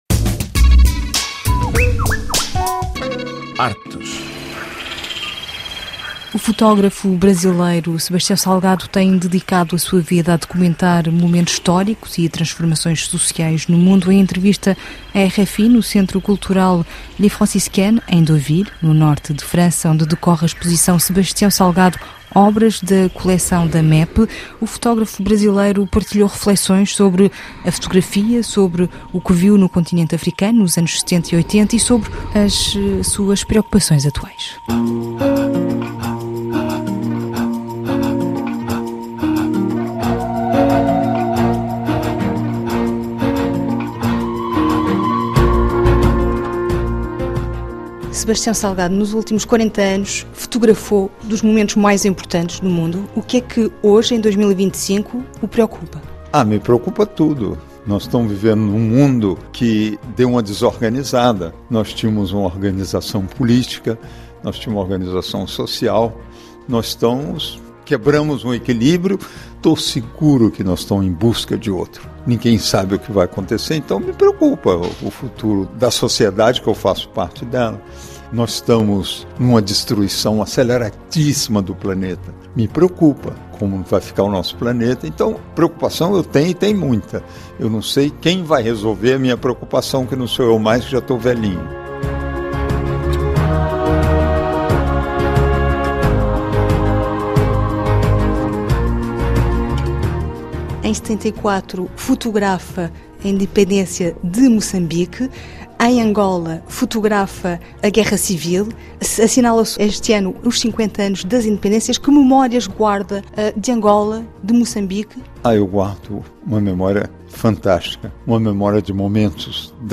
Em entrevista à RFI, no espaço da exposição, Sebastião Salgado fala com a mesma intensidade com que fotografa.